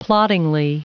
Prononciation du mot ploddingly en anglais (fichier audio)
Prononciation du mot : ploddingly